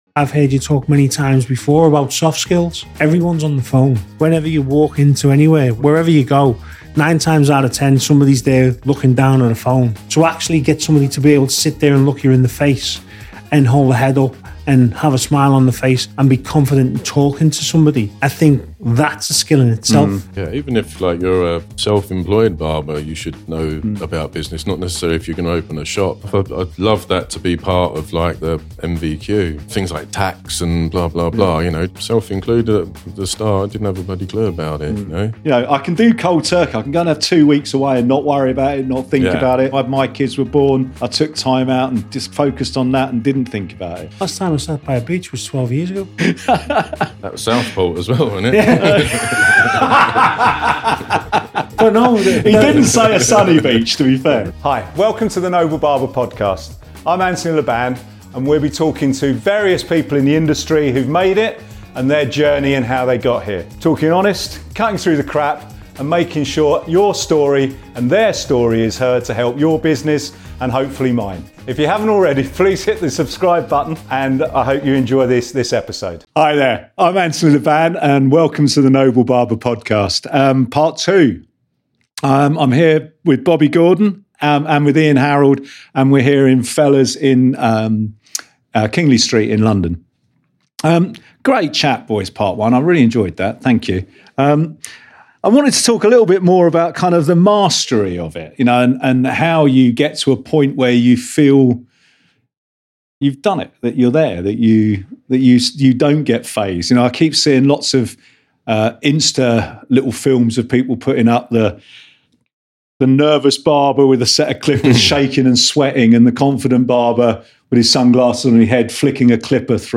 Joined weekly by industry professionals to talk about barbering, becoming and maintaining a barber shop, hiring, employment, money and cutting through the crap of the industry.